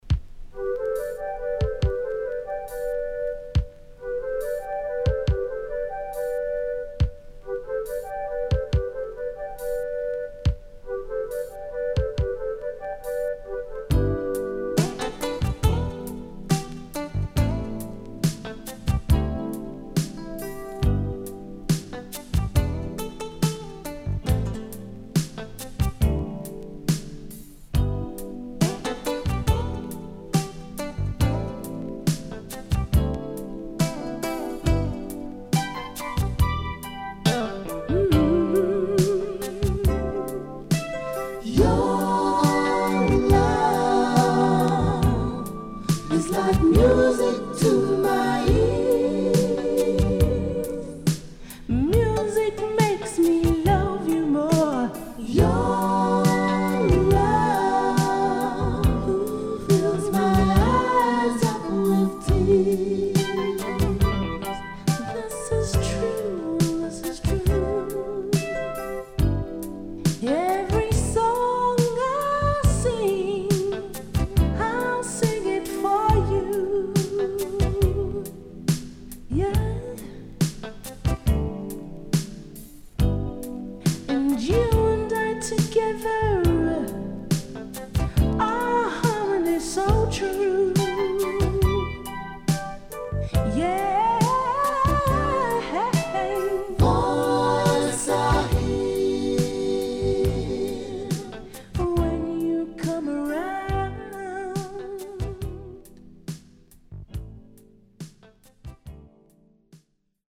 【12inch】
Nice Female Lovers
SIDE A:少しチリノイズ入りますが良好です。